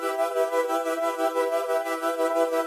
SaS_MovingPad04_90-E.wav